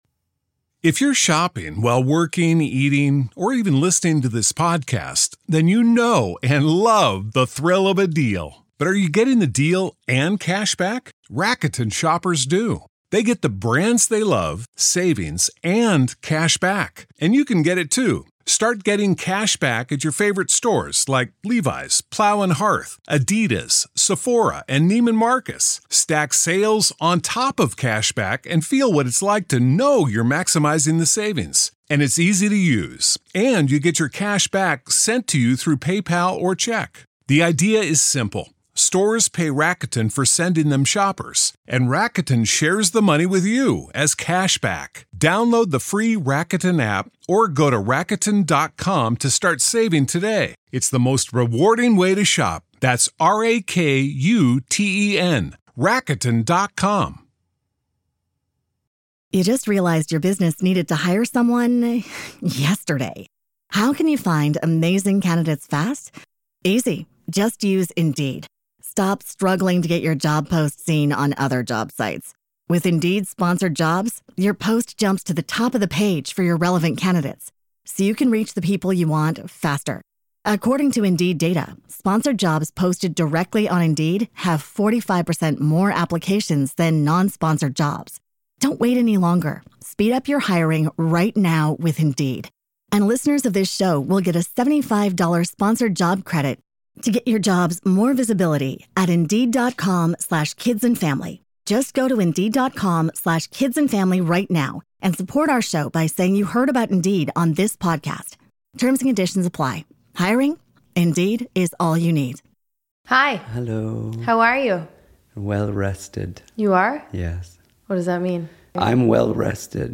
This week on the podcast, we are on location, getting pedicures & talking about the importance of self care.